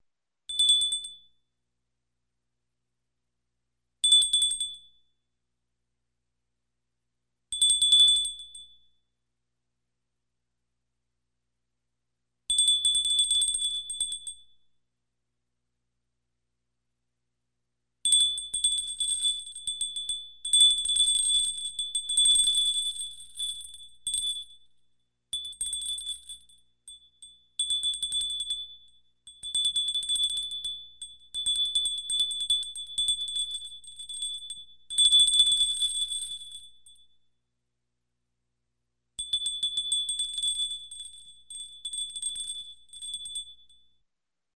Звонки звуки скачать, слушать онлайн ✔в хорошем качестве